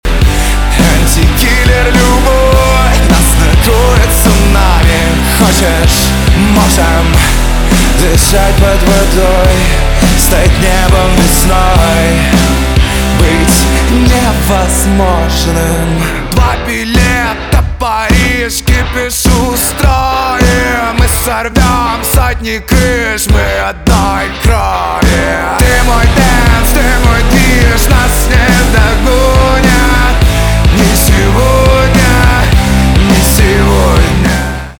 поп
гитара , барабаны , рок
качающие